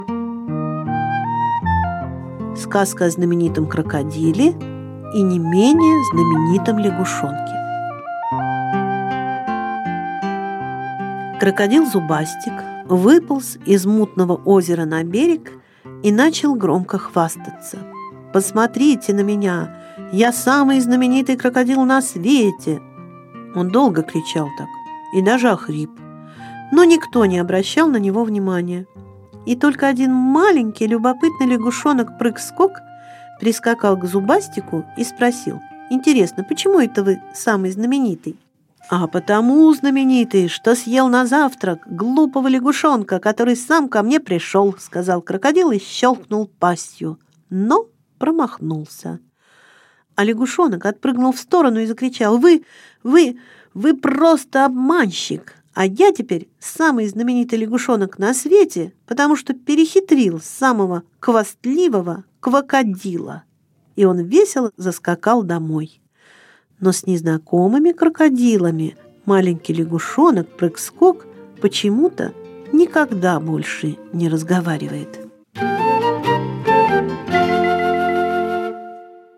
Сказка о знаменитом крокодиле и не менее знаменитом лягушонке - аудиосказка Пляцковского - слушать онлайн | Мишкины книжки